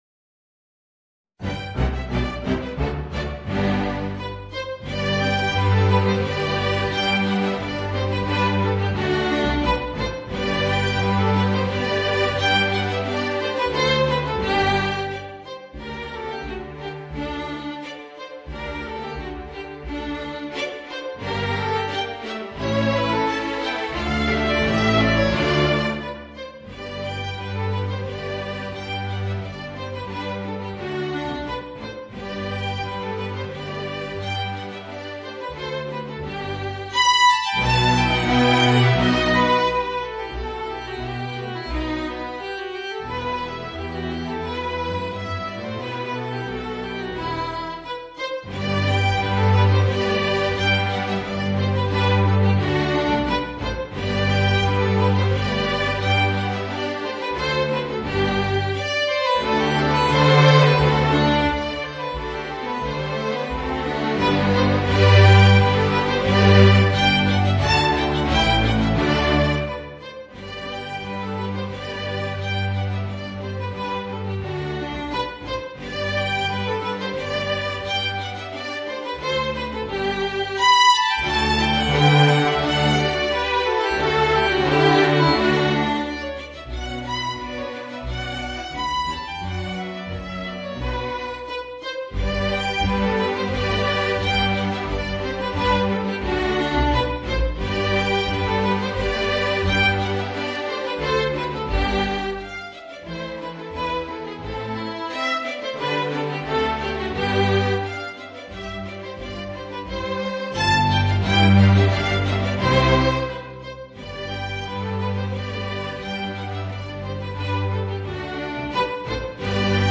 Voicing: Tenor Saxophone and String Orchestra